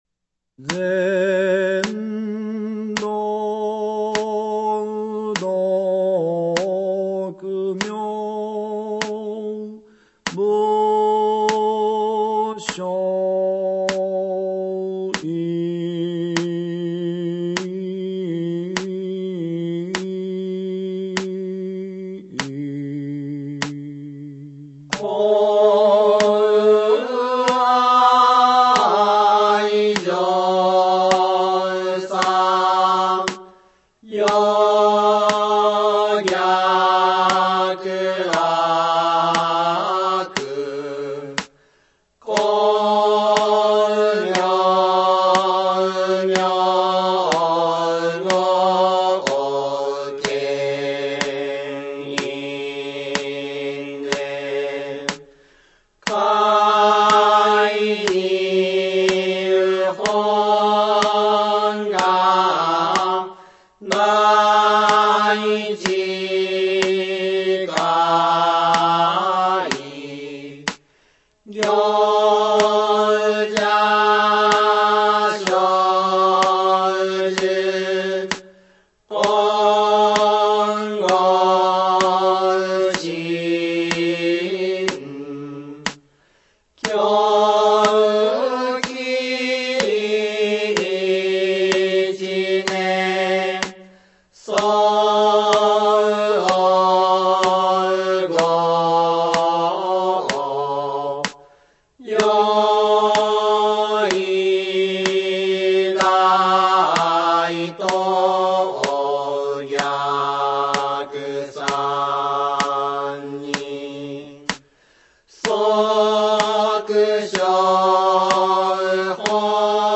shoshingue_ritmo_gyofu.mp3